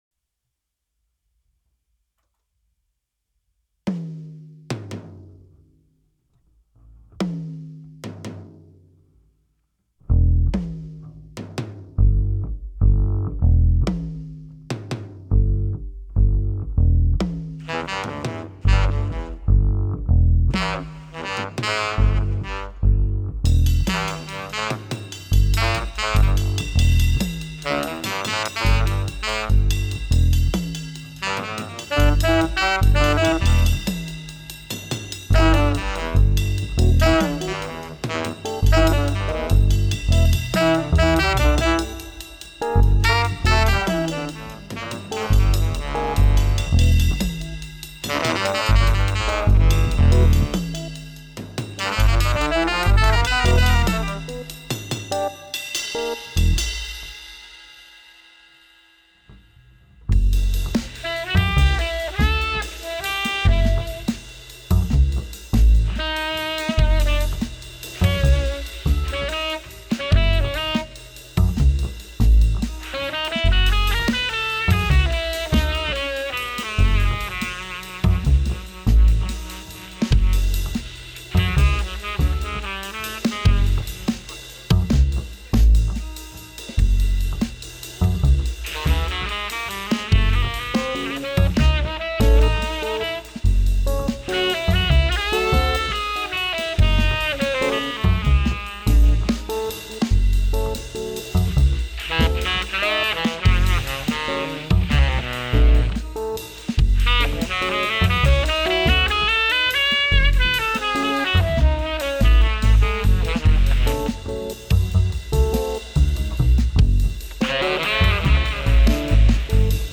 AMBIENT FUSION //
kontrabass/guitar und tenorsax/keys suchen kreative und experimentierfreudige drums/percussion > 40 (no pro)
eigene stücke / electronic / live loops / viel raum zum improvisieren